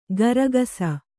♪ garagasa